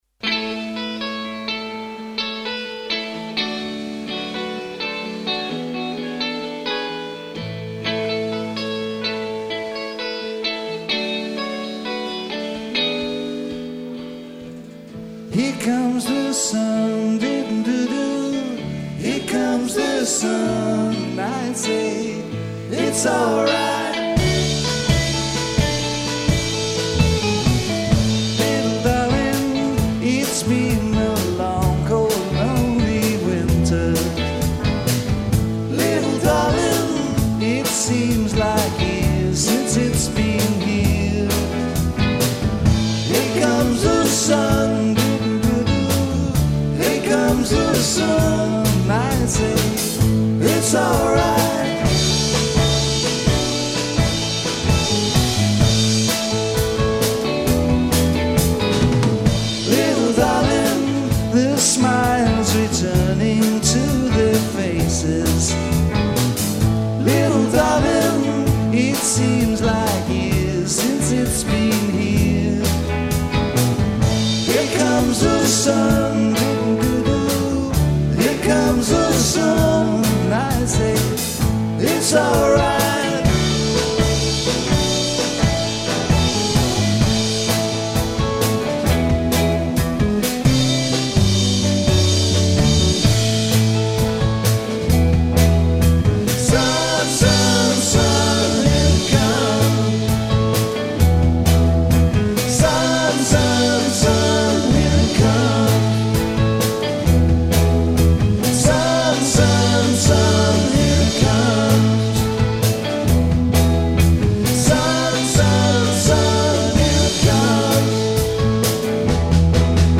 Live recording-